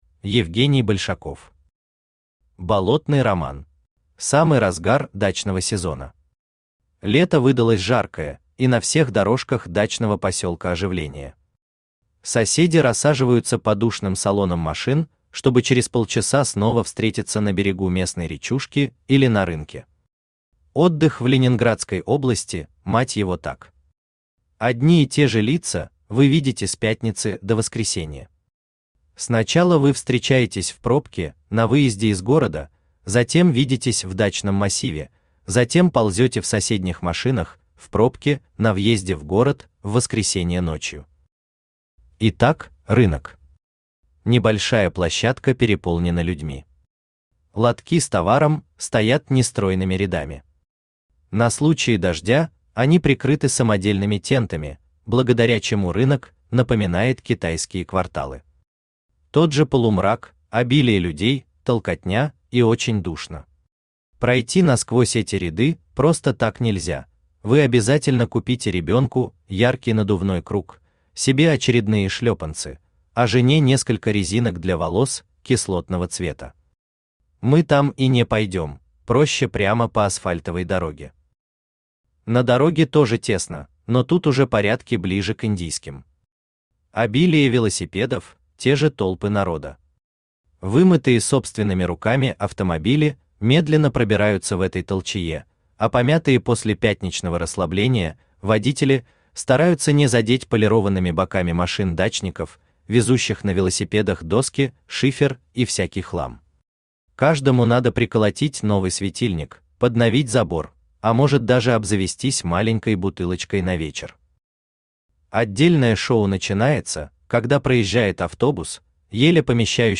Аудиокнига Болотный роман | Библиотека аудиокниг
Aудиокнига Болотный роман Автор Евгений Игоревич Большаков Читает аудиокнигу Авточтец ЛитРес.